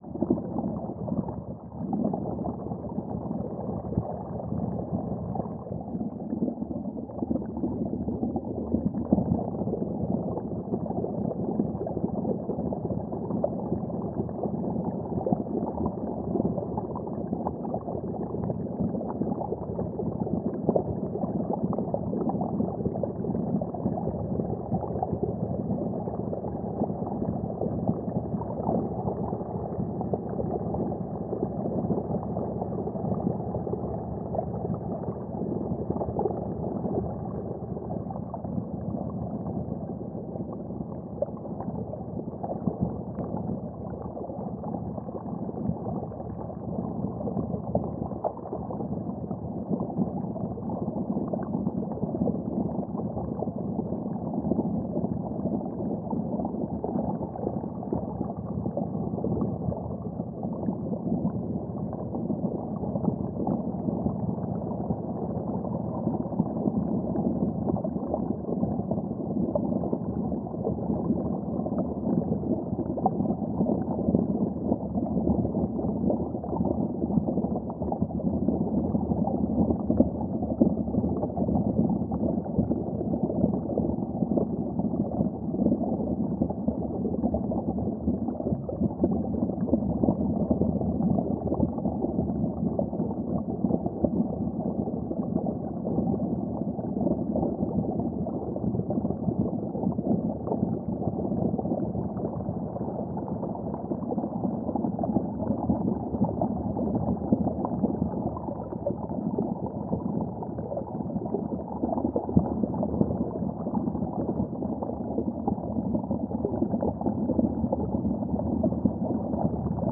Dive Deep - Small Bubbles 09.wav